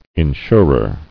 [in·sur·er]